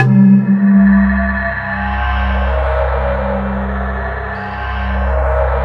Index of /90_sSampleCDs/USB Soundscan vol.13 - Ethereal Atmosphere [AKAI] 1CD/Partition D/04-ACTUALSYN